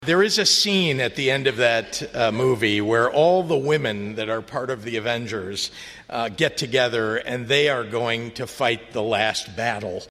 Governor Pritzker compared the fight over abortion rights to a comic book film.